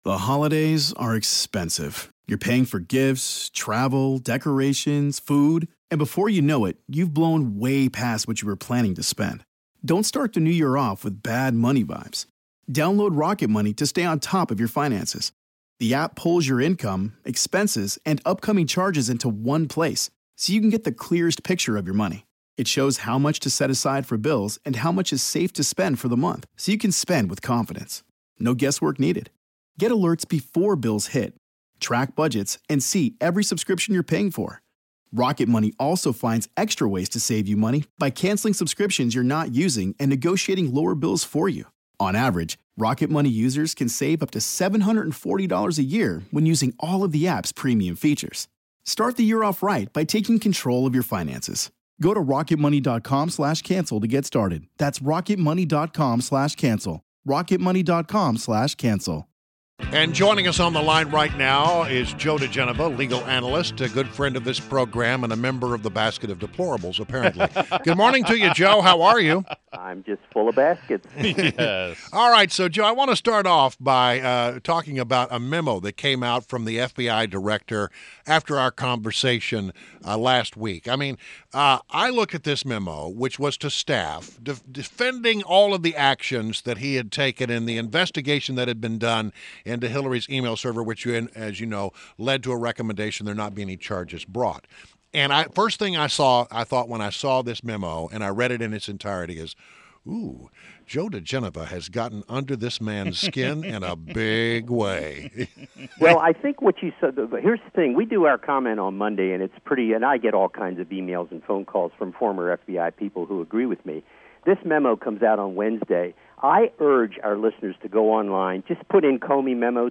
WMAL Interview - JOE DIGENOVA - 09.12.16